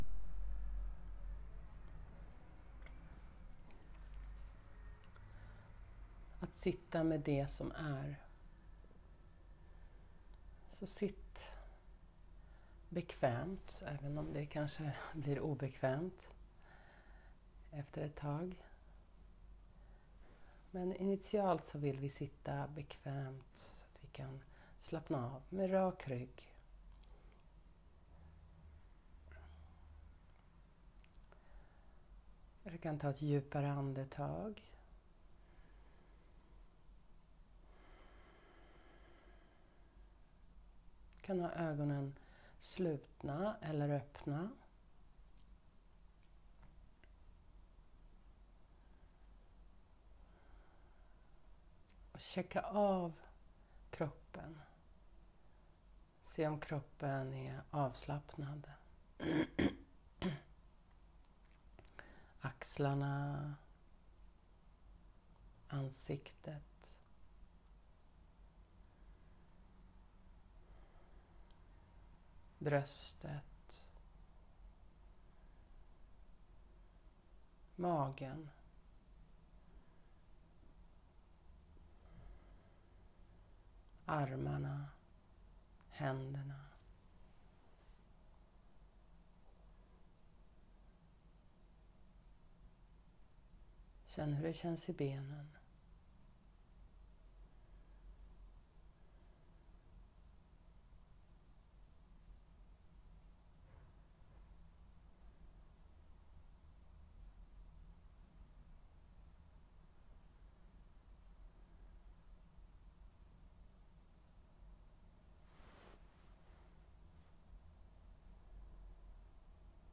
Jag spelade in den en tidig morgon och mediterar alltså samtidigt. Meditationen kan sänka dina stresshormoner rejält, testa att göra den när som helst. Du kan sitta eller ligga ner, antingen hemma, på jobbet (lunchen) på ett kafé eller tåget.